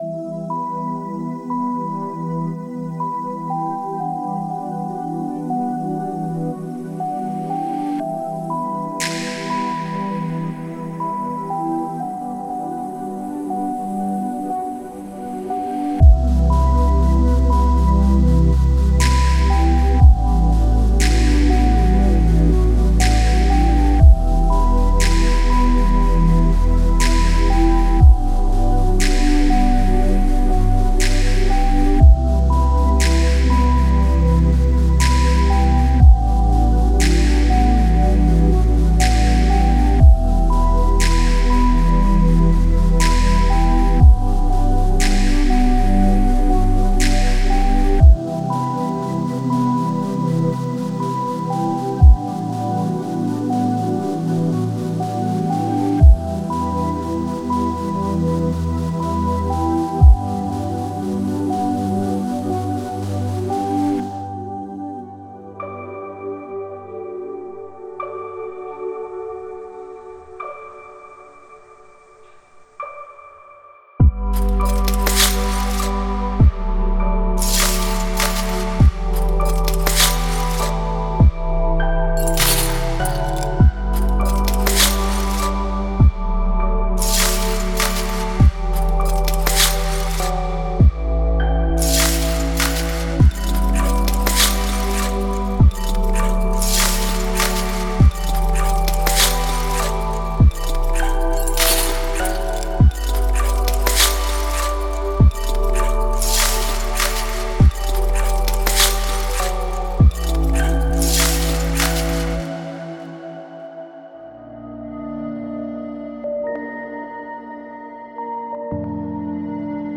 Ambient Chill Out / Lounge Cinematic / FX